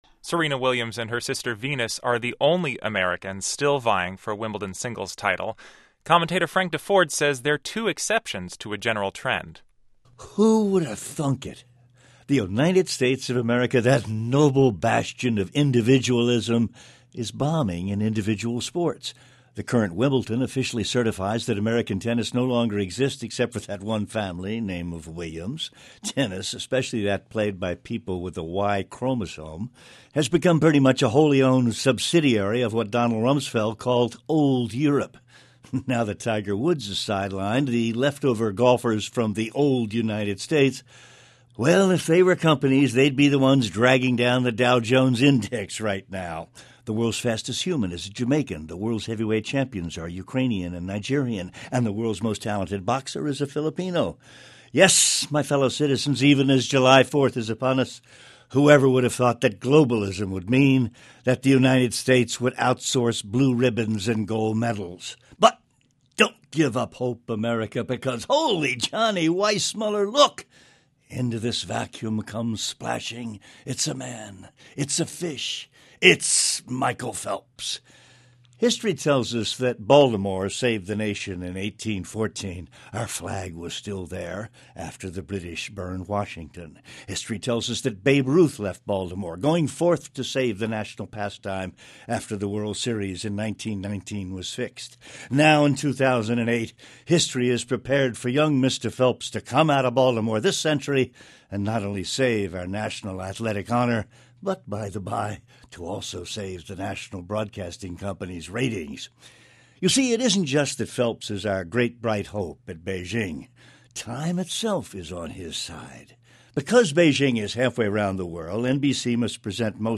NPR Morning Edition's Frank Deford gives weekly commentary on a cross section of the world of sports.